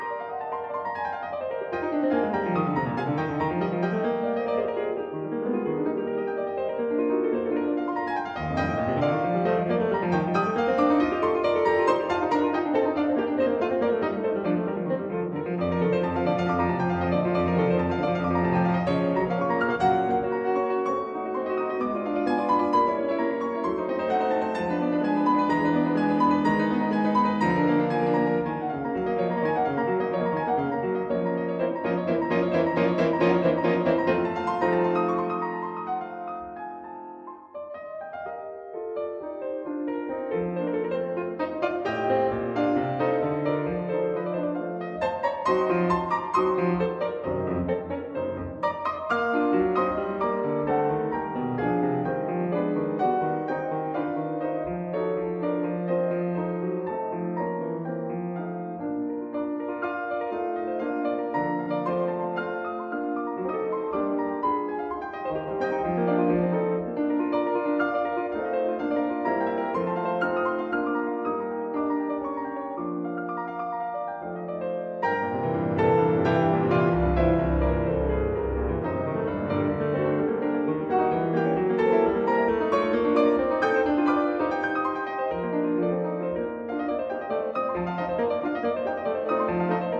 如油般流瀉的樂句與和聲，
旋律優美的享受，音符自在跳躍，